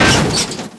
SCRAPE.WAV